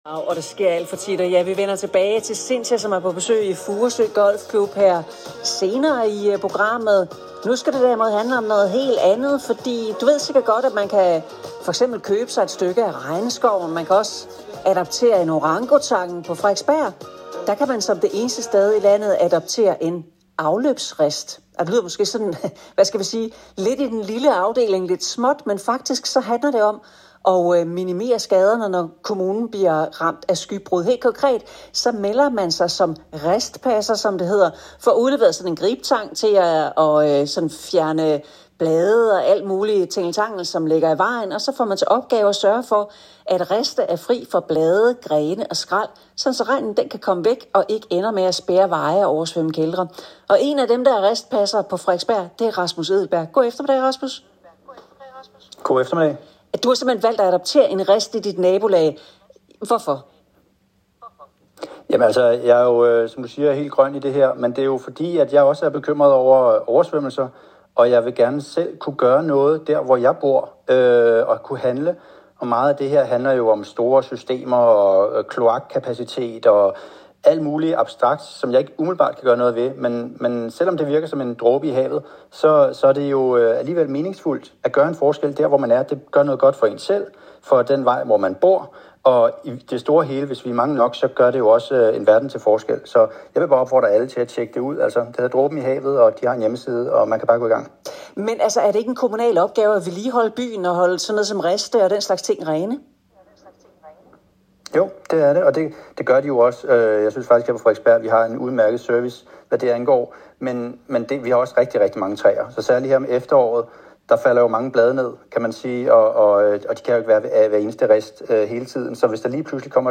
Interview med DR P4 (august 2025) om hvorfor jeg blev ristpasser: